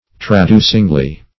Search Result for " traducingly" : The Collaborative International Dictionary of English v.0.48: Traducingly \Tra*du"cing*ly\, adv.